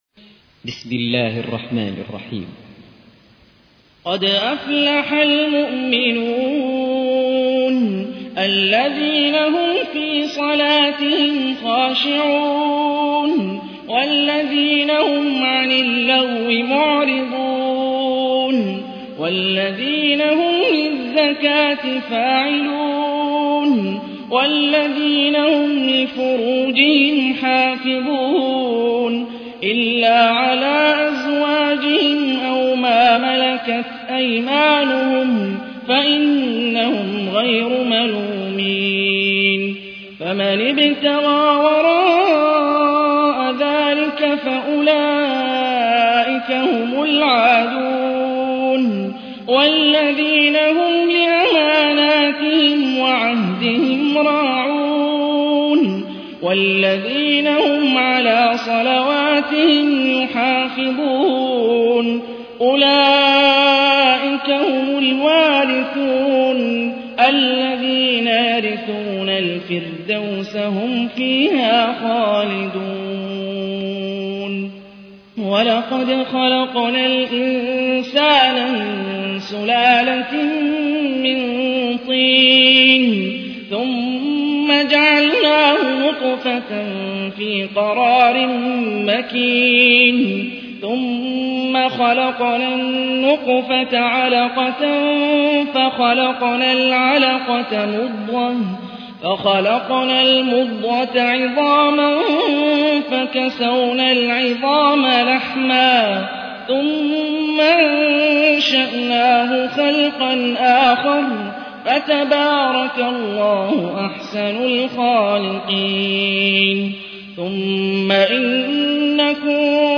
تحميل : 23. سورة المؤمنون / القارئ هاني الرفاعي / القرآن الكريم / موقع يا حسين